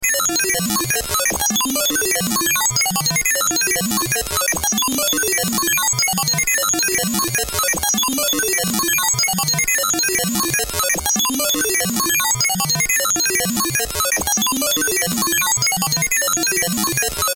昔っぽいコンピュータで情報を処理しているときのイメージ電子音。